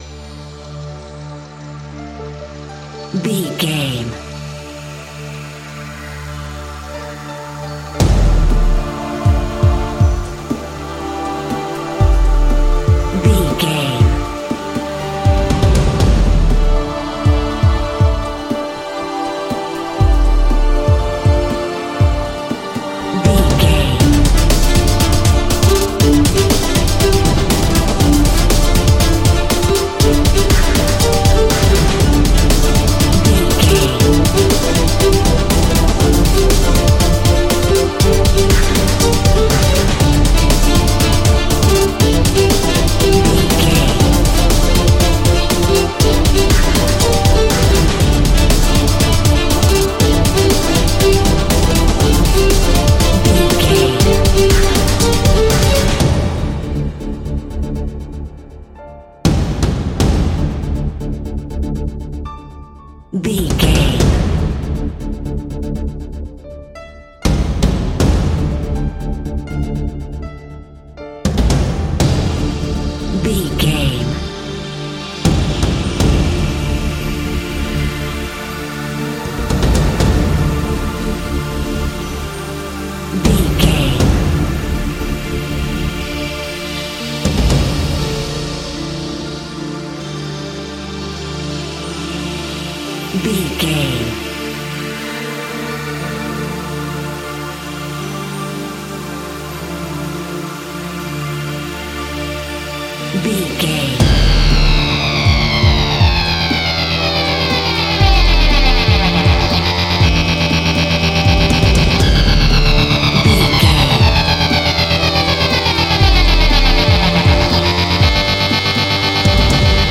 Aeolian/Minor
strings
percussion
synthesiser
brass
cello